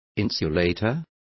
Complete with pronunciation of the translation of insulators.